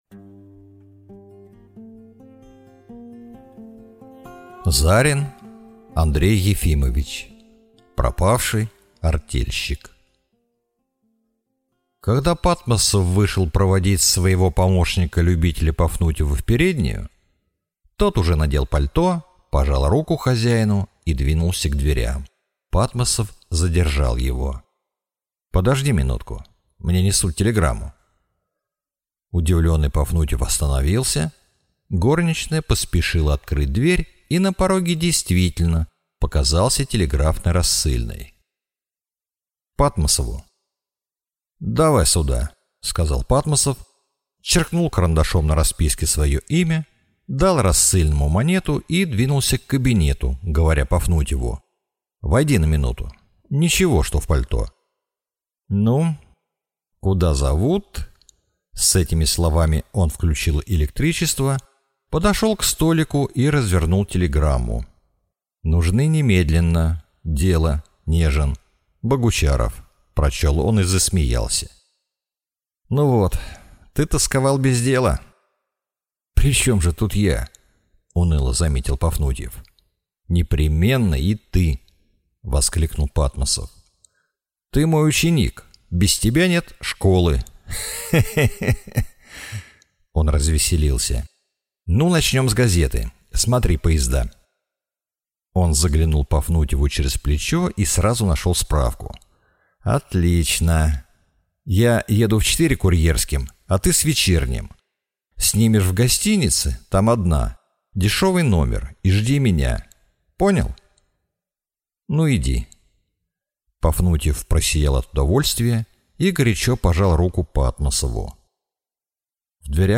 Аудиокнига Пропавший артельщик | Библиотека аудиокниг